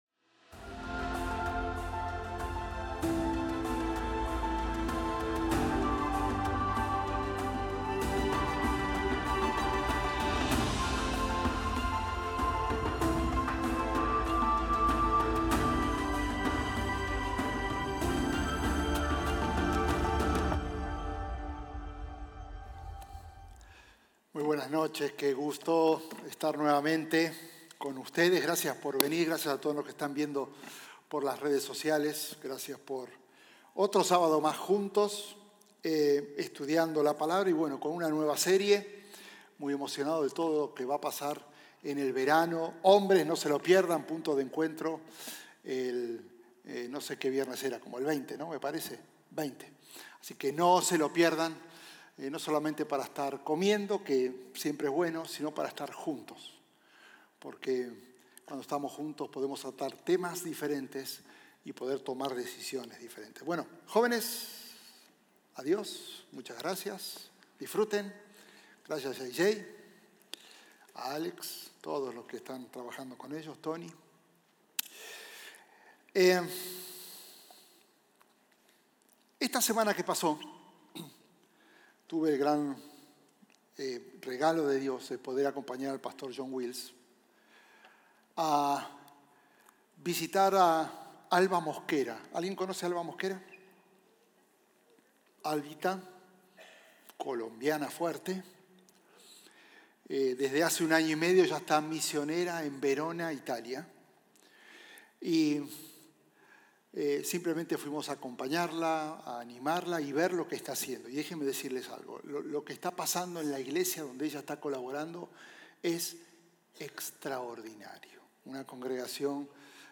Sermones North Klein – Media Player